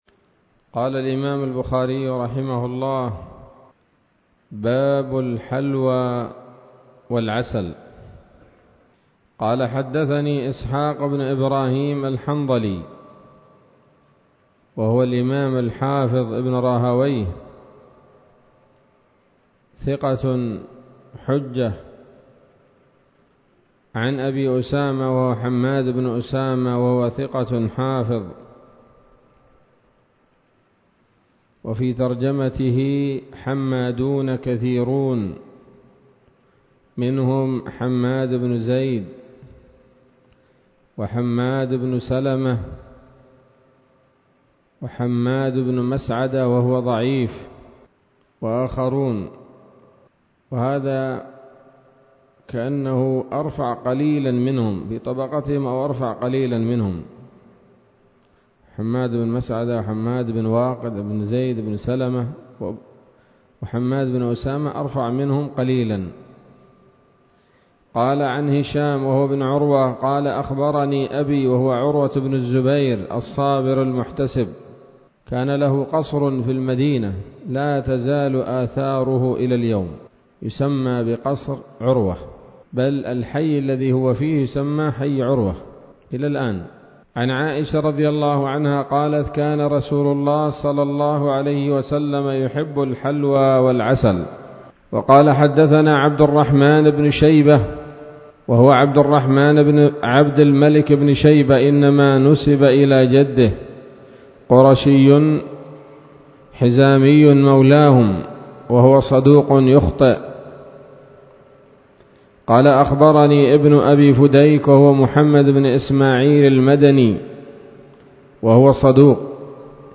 الدرس العشرون من كتاب الأطعمة من صحيح الإمام البخاري